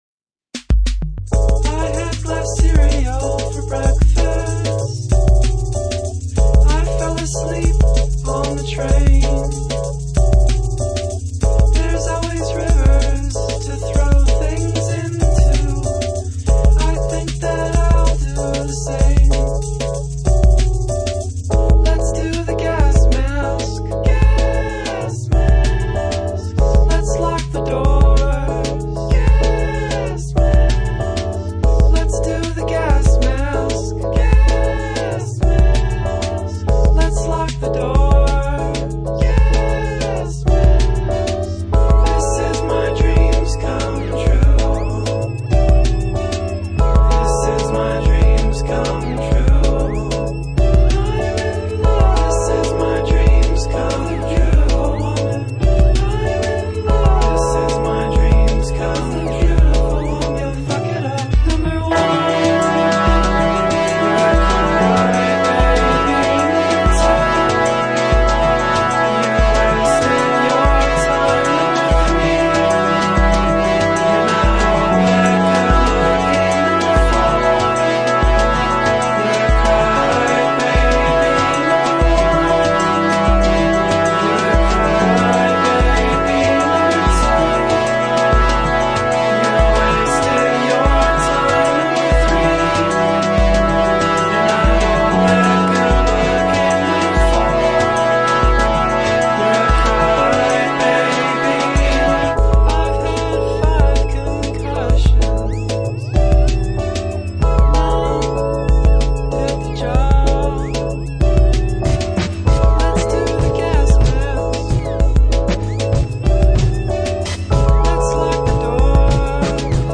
Midwestern avant-pop ensemble